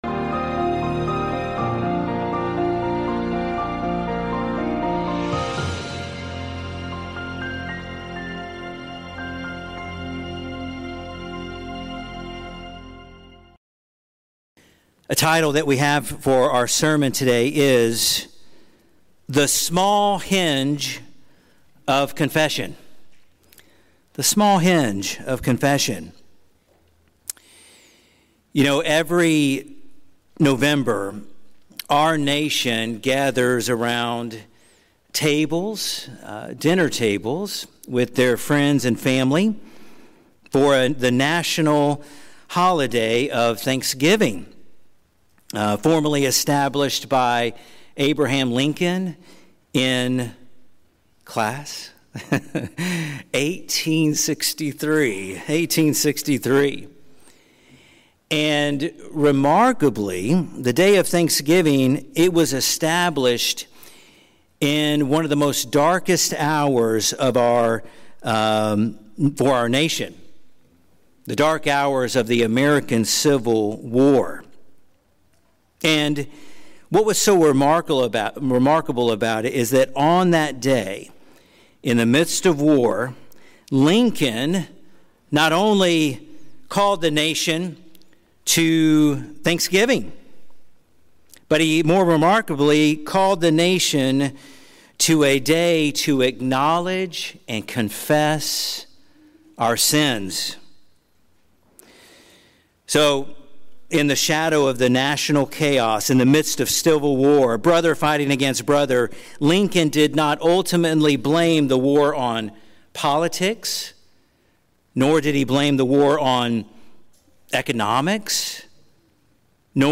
Drawing from Psalm 32 and 1 John 1:9, this sermon explains that the heavy burden of guilt and spiritual turmoil continues when sin remains unconfessed, but true peace and forgiveness come when we humbly bring our sins before God. Like a great door that swings open on the small hinge of the word “if,” confession opens the way for God’s mercy, cleansing, and restored joy!